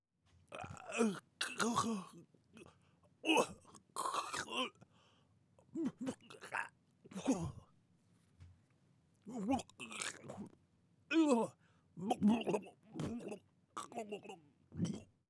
_drowning-man.wav